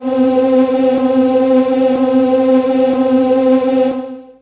55be-syn11-c3.wav